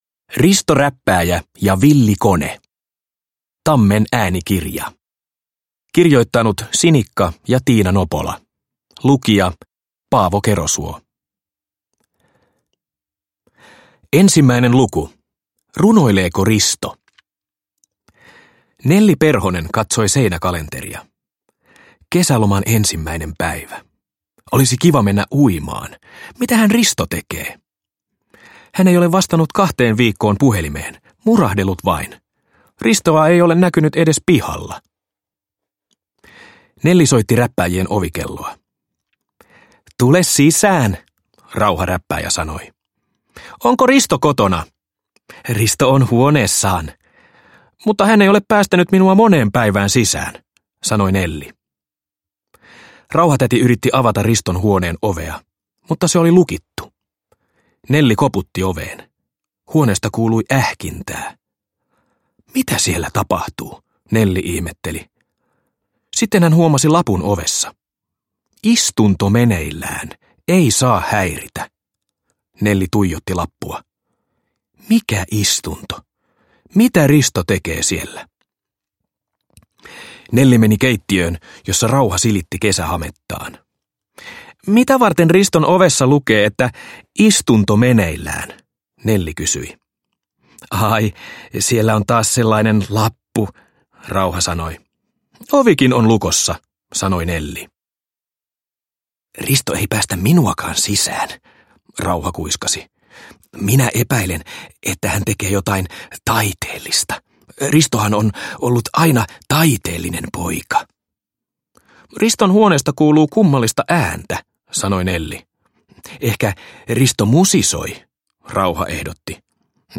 Risto Räppääjä ja villi kone – Ljudbok – Laddas ner